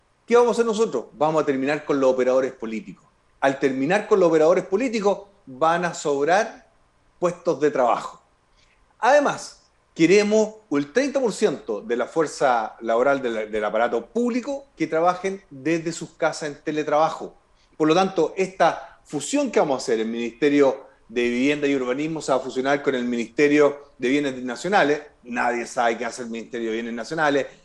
Con entrevista a Franco Parisi finalizó Presidenciales en Medios UdeC - Radio UdeC